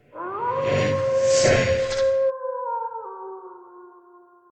save.ogg